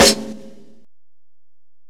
sfogo_snr.wav